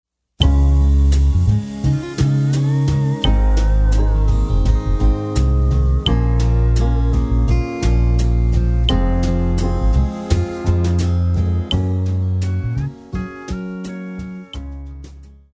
Classical
Band
Etude,Course Material,Classical Music
Only backing